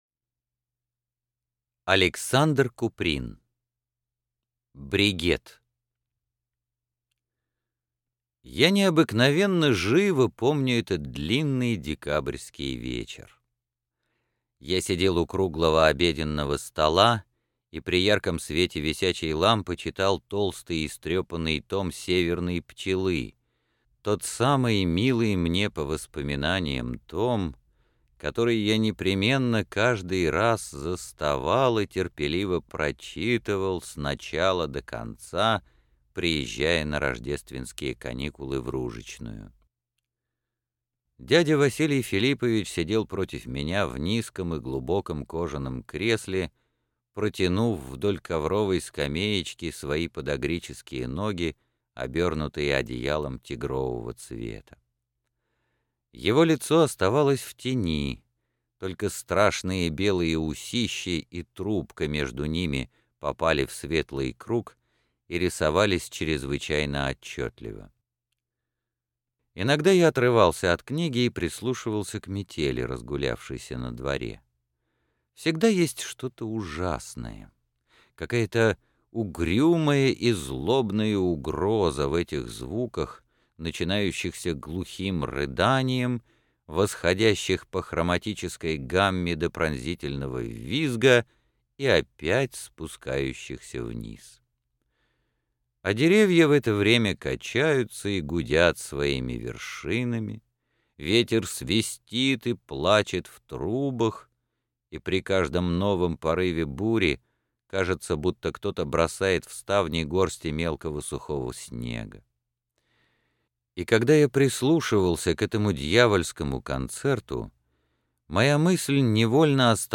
Аудиокнига Повести и рассказы | Библиотека аудиокниг